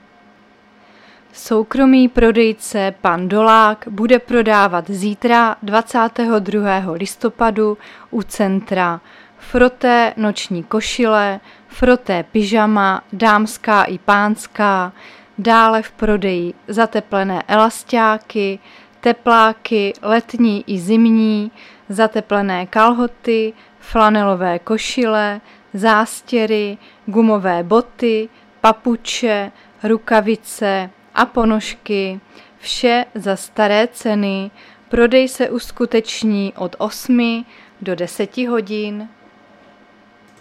Záznam hlášení místního rozhlasu 21.11.2022